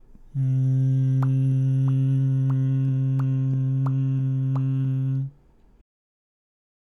※見本のチョキの声(口パクパク編)
「んー」と鼻から声を出しながらの口の開閉をやってもらいます。